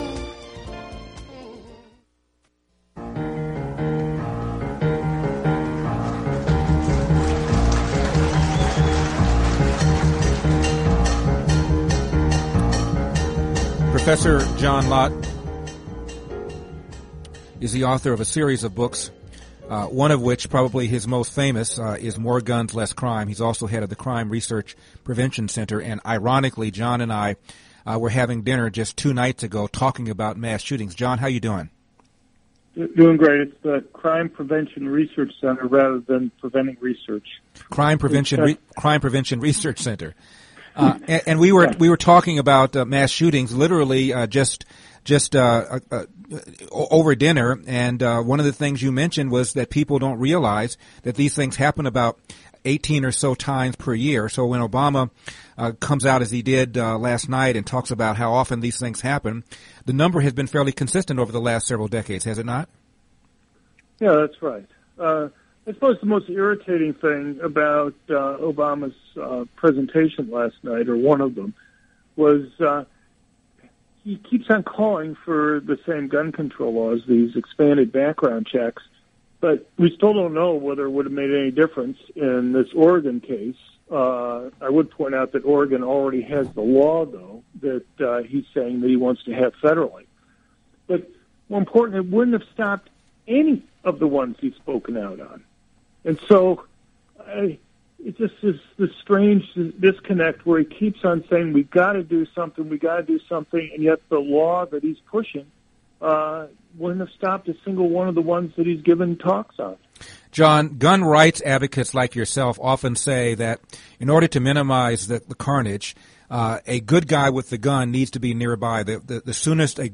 John Lott talked to Larry Elder on his radio show on KRLA about the Oregon shooting (Friday, October 2, 2015, 3:30 to 3:50 PM).